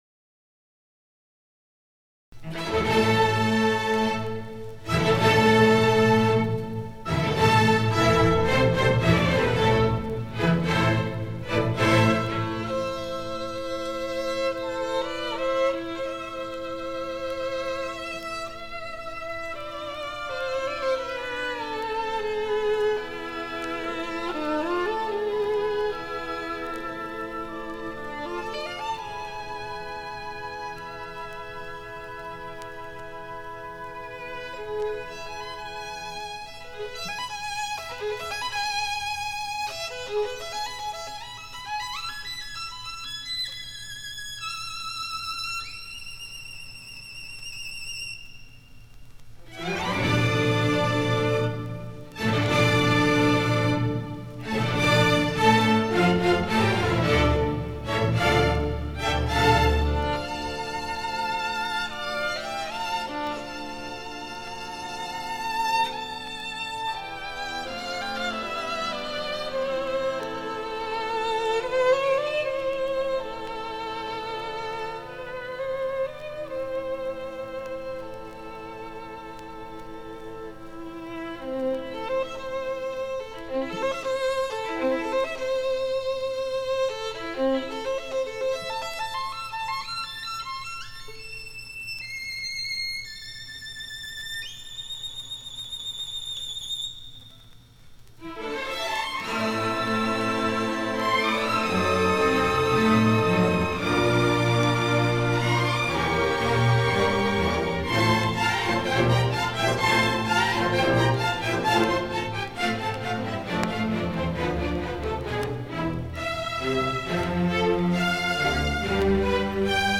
1977 Music in May orchestra and band performance recording · Digital Exhibits · heritage
It brings outstanding high school music students together on the university campus for several days of lessons and events, culminating in the final concert that this recording preserves.